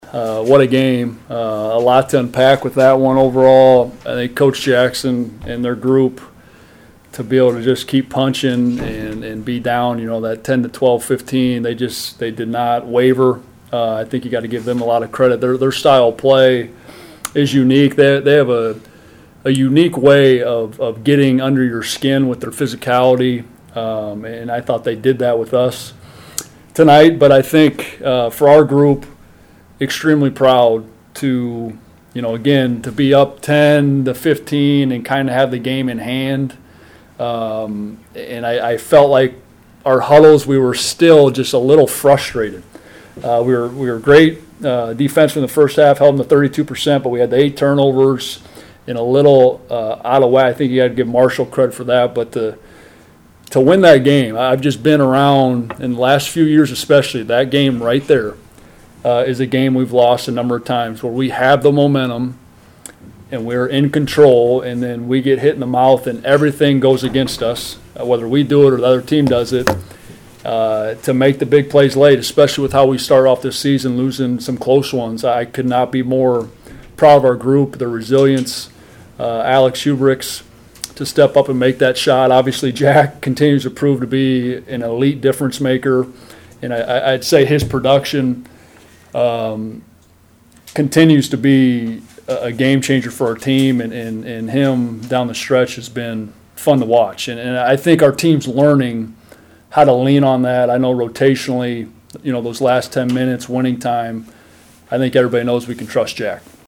POSTGAME PRESS CONFERENCE EXCERPTS